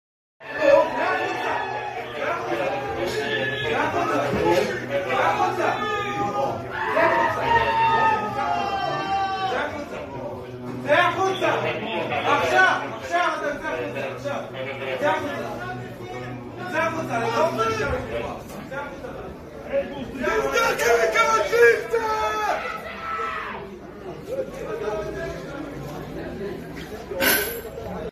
Israeli police brutality in synagogue Jerusalem